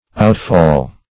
Outfall \Out"fall`\ (out"f[add]l`), n.